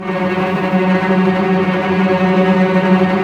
Index of /90_sSampleCDs/Roland LCDP13 String Sections/STR_Vcs Tremolo/STR_Vcs Trem f